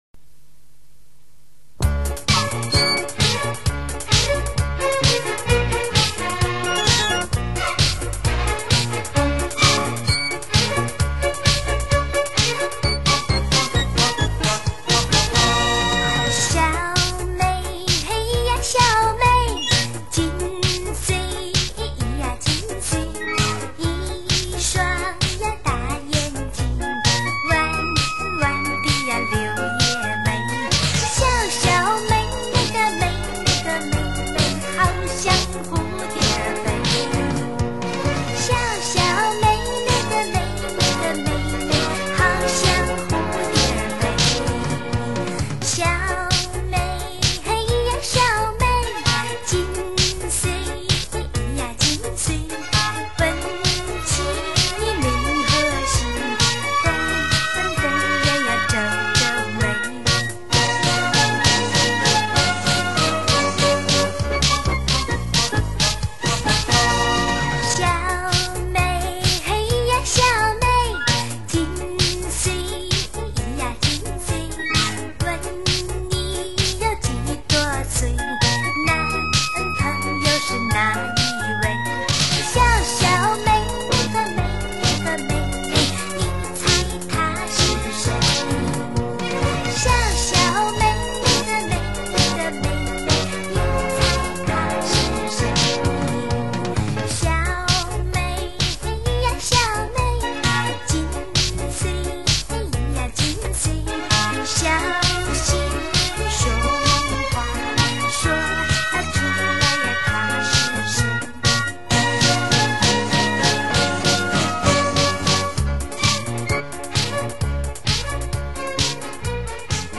可惜这些歌曲都是从磁带转录的，效果并不是很理想。